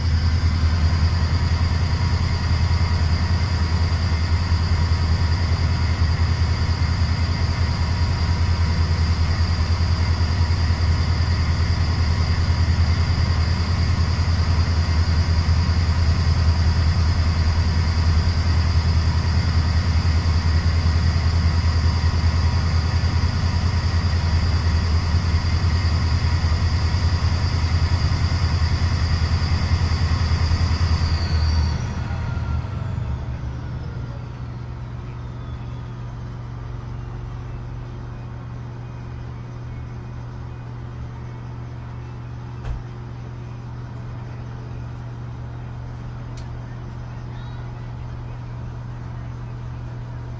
纽约市" 船只引擎小渡船bassy 美国纽约市
描述：船引擎小渡轮低音NYC，USA.flac
标签： 低音加重 NYC 渡轮 USA 发动机
声道立体声